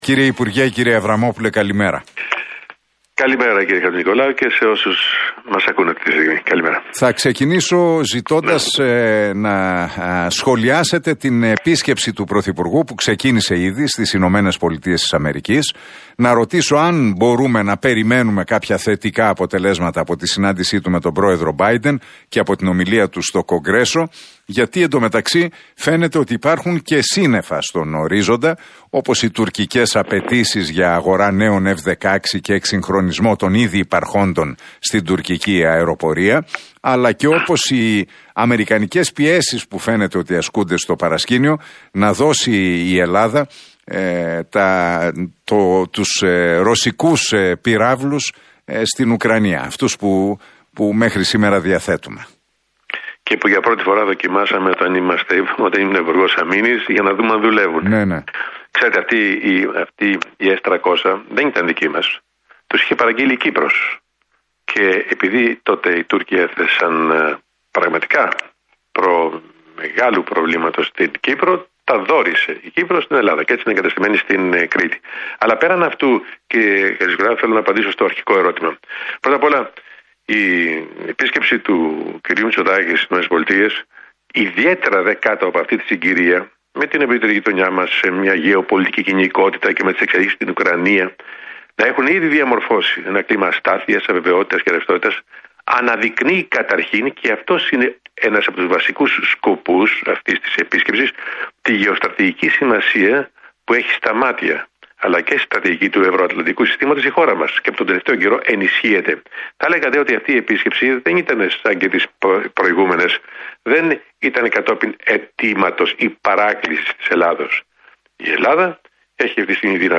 Ο πρώην Επίτροπος Μετανάστευσης, Εσωτερικών Υποθέσεων και Ιθαγένειας της Ευρωπαϊκής Ένωσης, Δημήτρης Αβραμόπουλος, παραχώρησε συνέντευξη στον Realfm 97,8 και στον Νίκο Χατζηνικολάου.